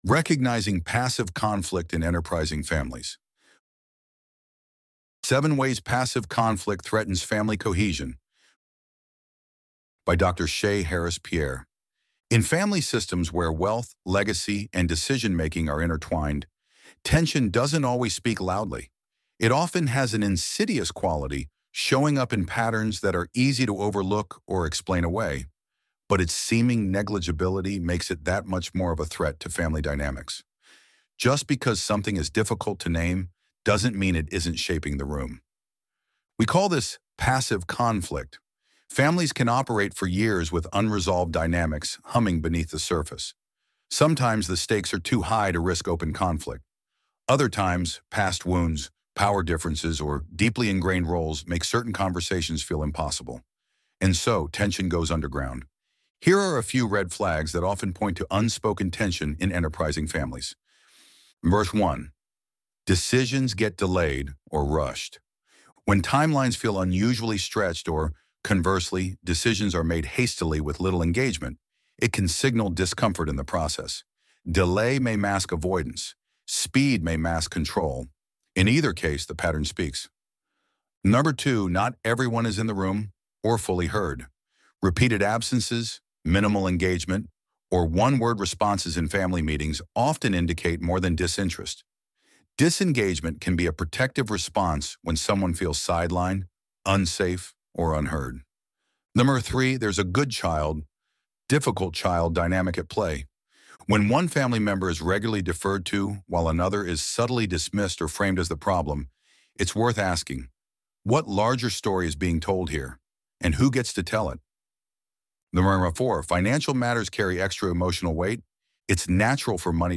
Recognizing Passive Conflict in Enterprising Families 7 Ways Passive Conflict Threatens Family Cohesion Loading the Elevenlabs Text to Speech AudioNative Player...